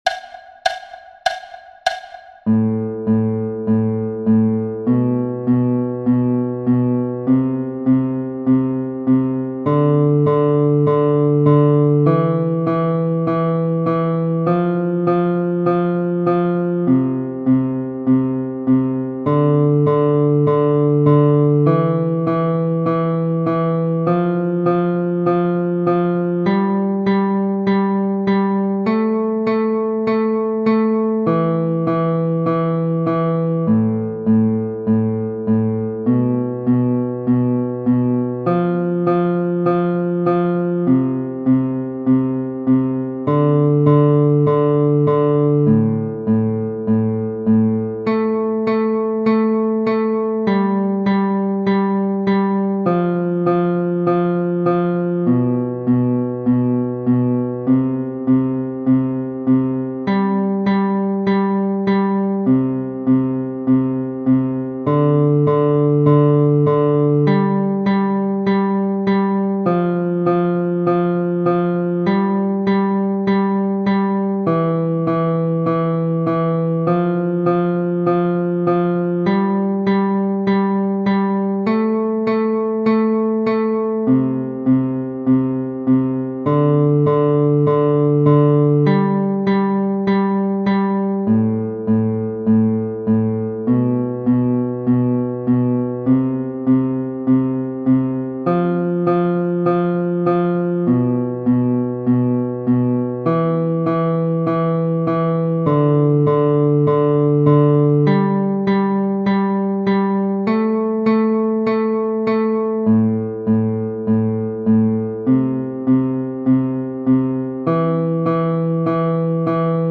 Four clicks and then you’re off and running. or plucking.
100 BPM
String 5 – Roots Only – Natural Notes
Practice_for_Notes_on_String_5_Natural_Notes-100.mp3